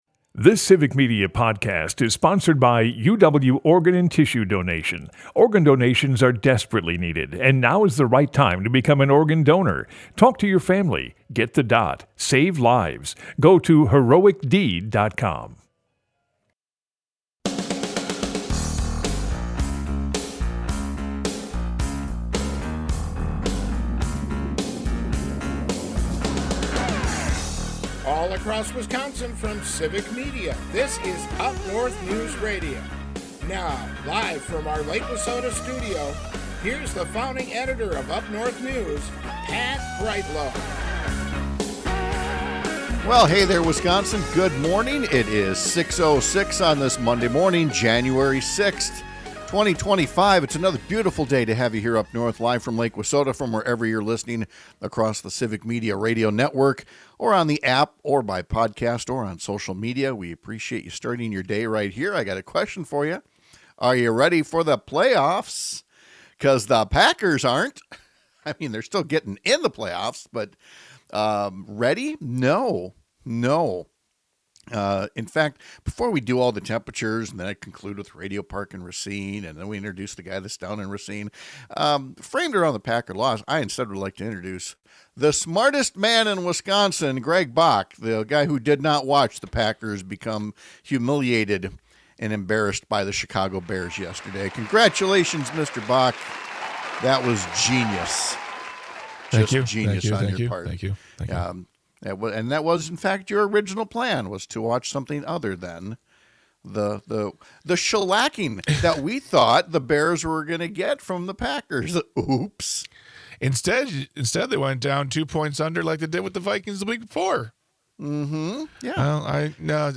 Pat Kreitlow is a longtime Wisconsin journalist and former state legislator who lives in and produces his show from along Lake Wissota in Chippewa Falls. UpNorthNews is Northern Wisconsin's home for informative stories and fact-based conversations. Broadcasts live 6 - 8 a.m. across the state!